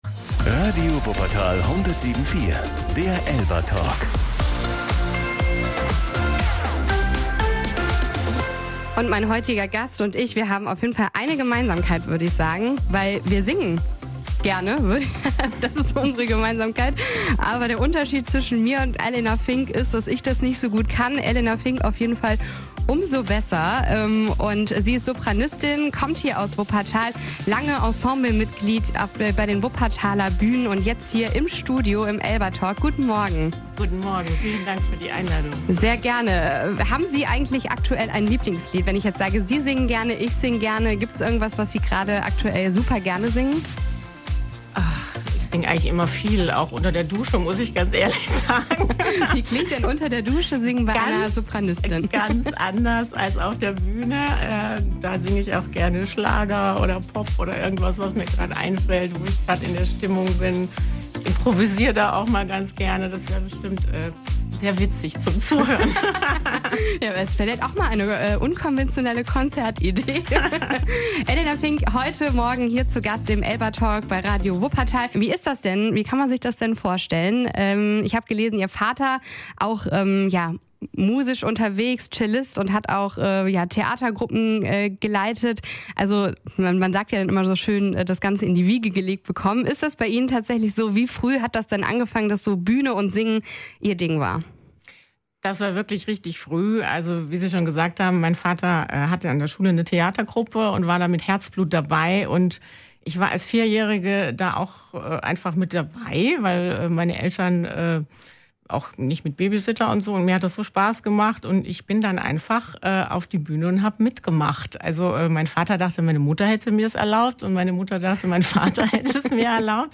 ELBA-Talk